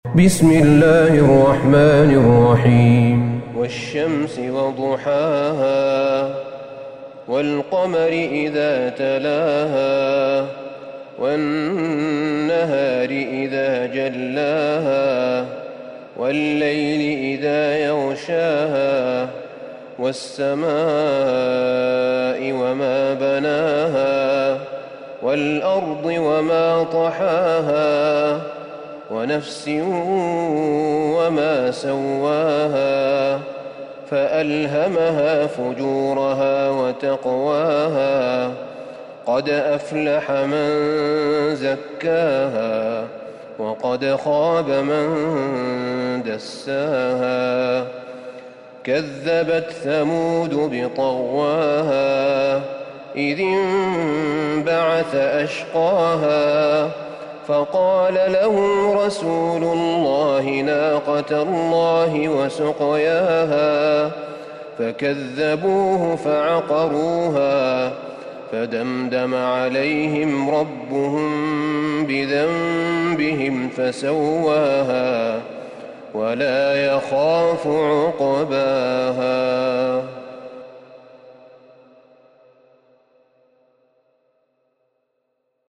سورة الشمس Surat Ash-Shams > مصحف الشيخ أحمد بن طالب بن حميد من الحرم النبوي > المصحف - تلاوات الحرمين